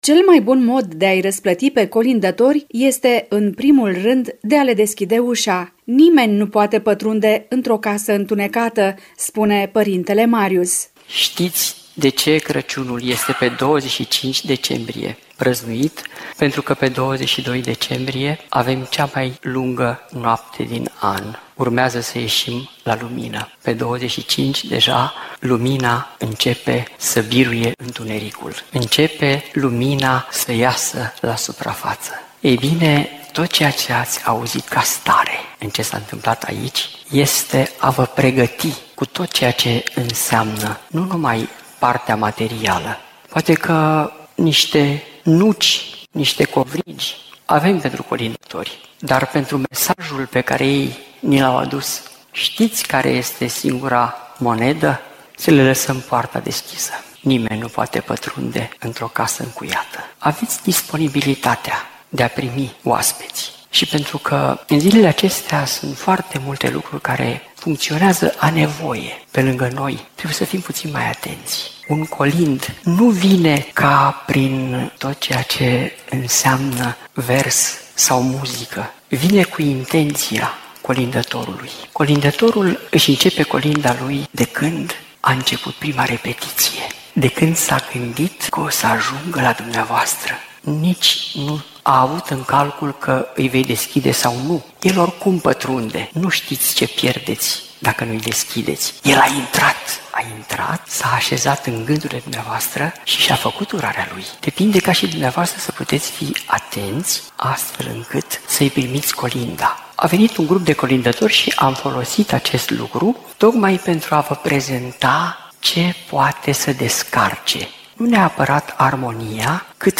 Emoție și trăire înaltă, spiritualitate, generozitate, bucuria de a fi împreună în prag de sărbători au caracterizat întâlnirea cu participanții constănțeni, care au umplut Aula bibliotecii.